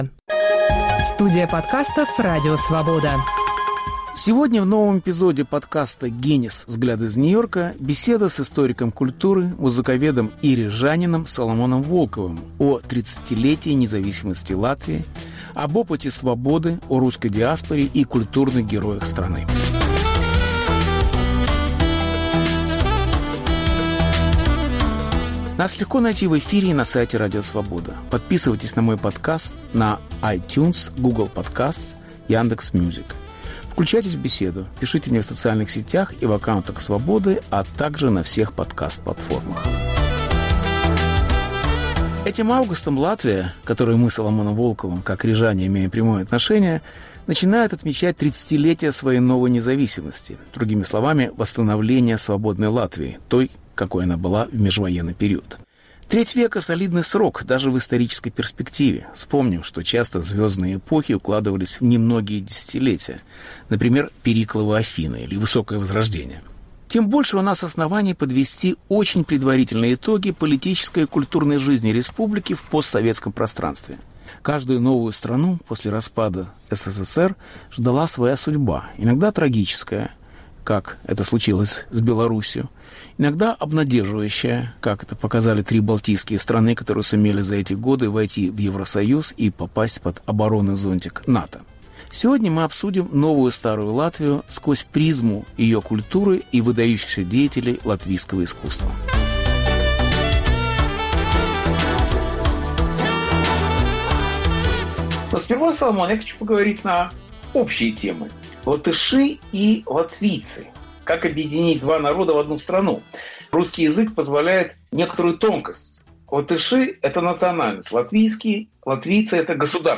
Беседа с Соломоном Волковым о культуре постсоветских республик